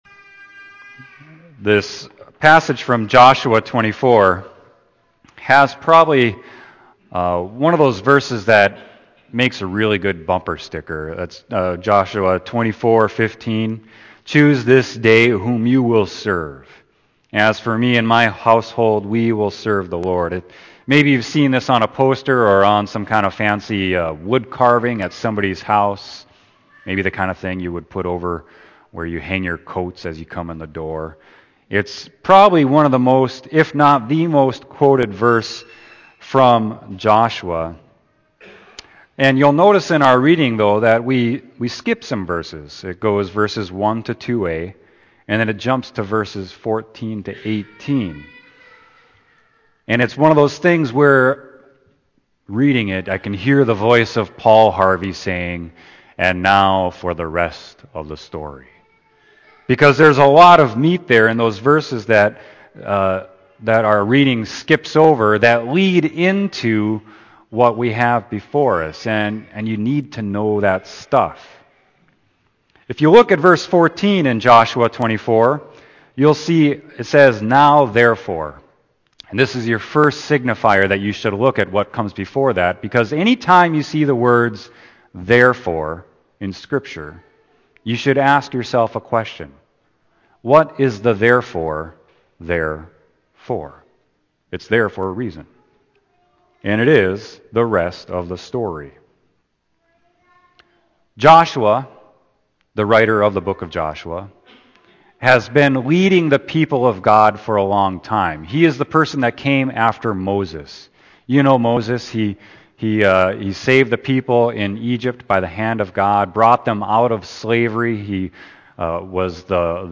Sermon: Joshua 24.1-2a, 14-18